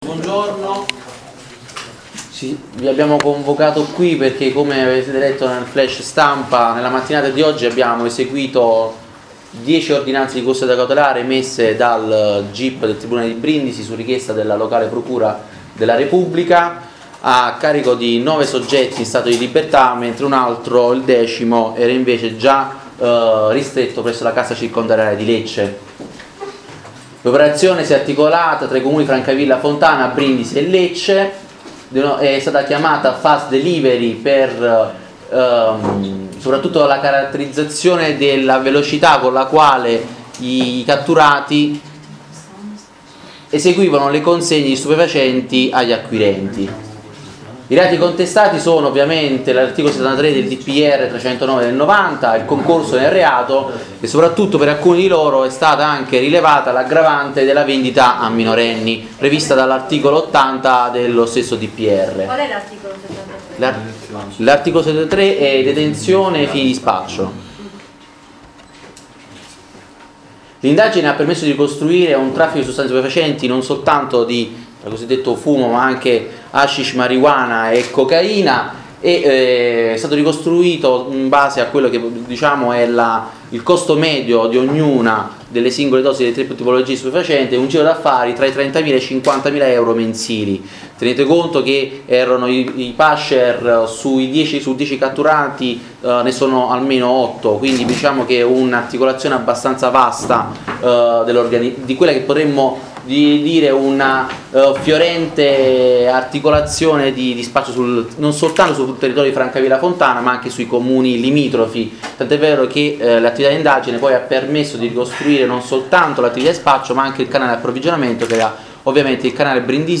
Conferenza stampa Carabinieri Brindisi